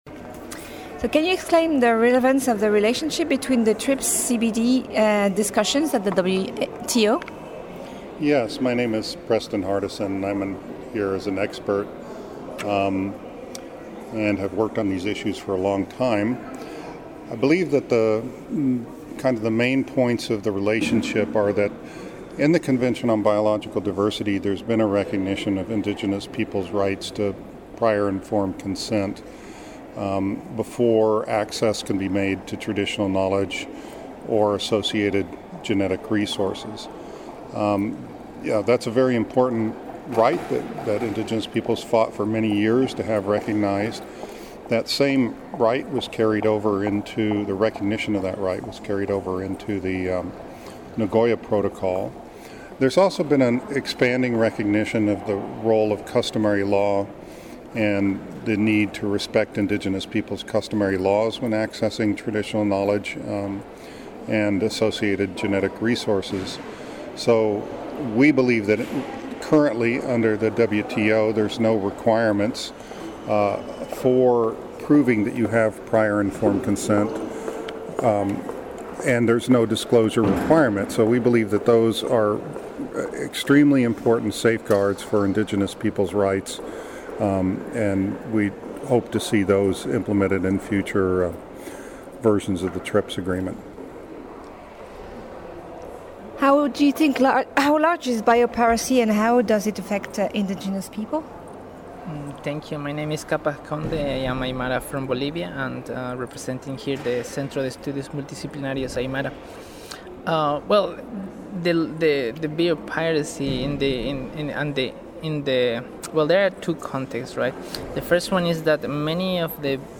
In a podcast and video interview with Intellectual Property Watch below, the indigenous representatives explain their case.
Interview-Indigenous-Peoples_01.mp3